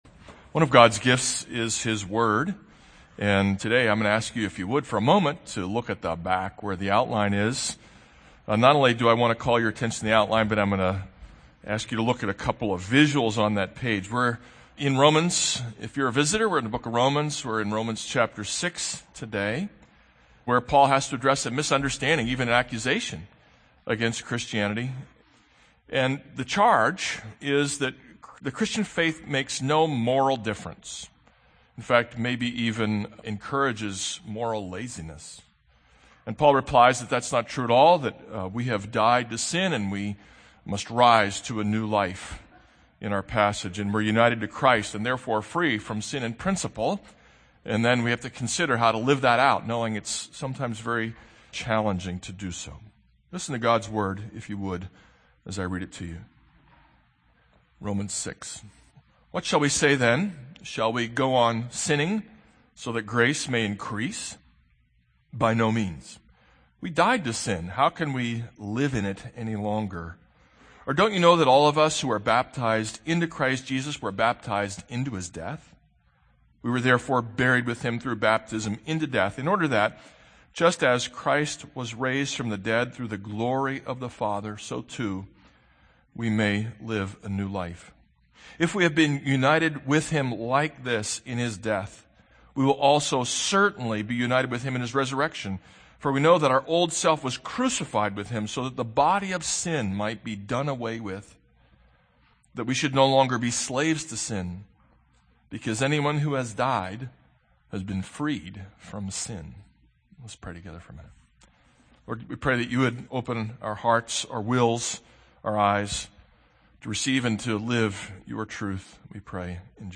This is a sermon on Romans 6:1-7.